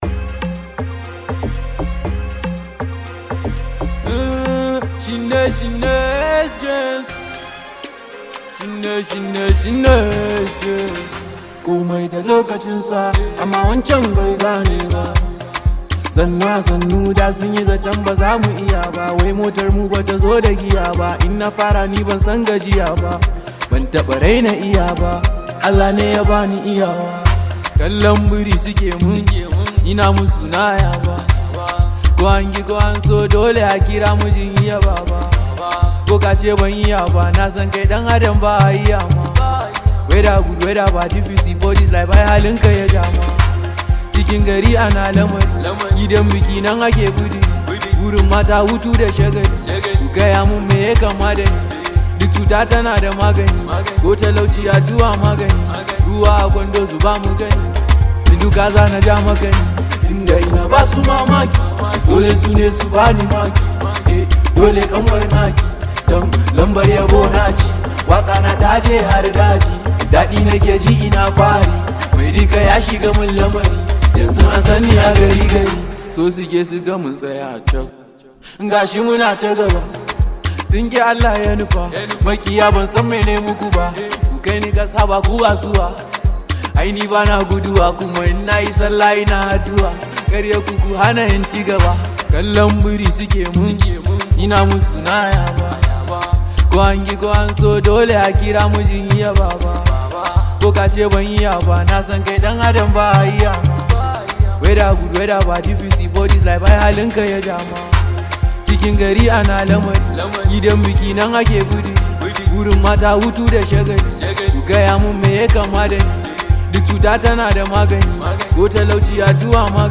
Hausa hip-hop